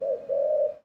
bird_pigeon_call_03.wav